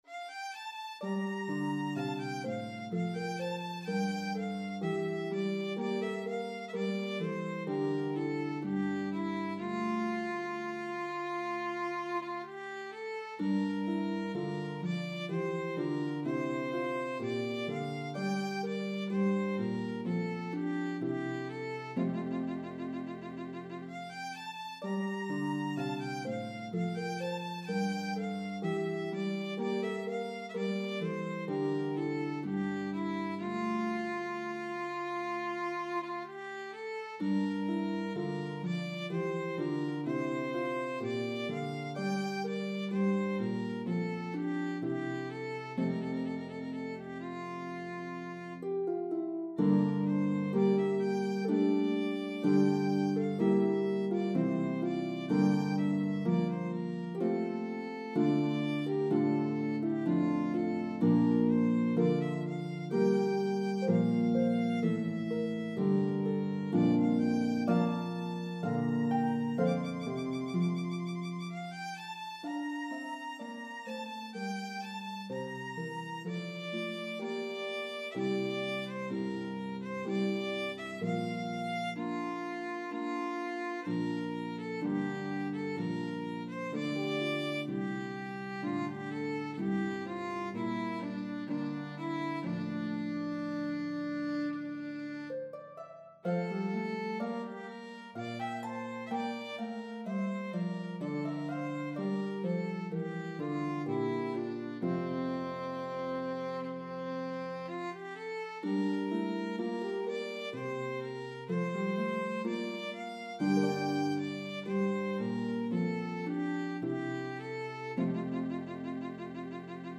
Italian Baroque style pieces
lovely slow air